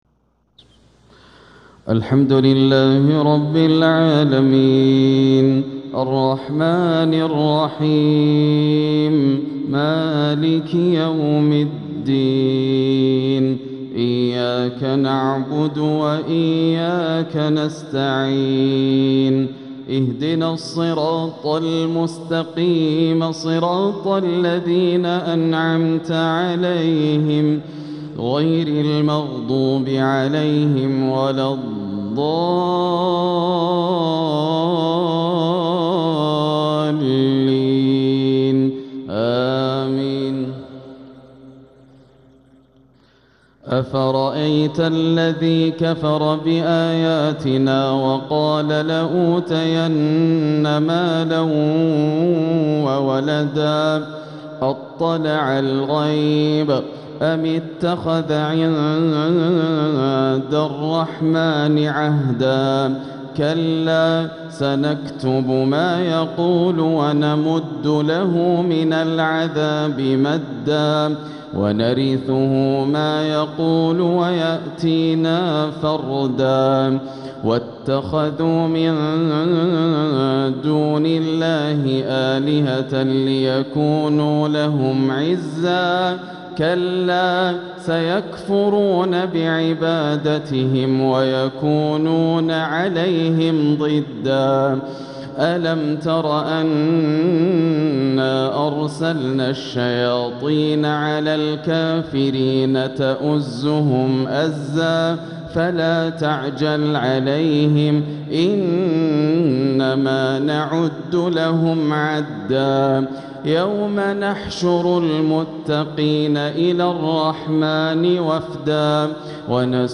تلاوة لخواتيم سورة مريم | عشاء 7-4-1447هـ > عام 1447 > الفروض - تلاوات ياسر الدوسري